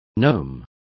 Complete with pronunciation of the translation of gnomes.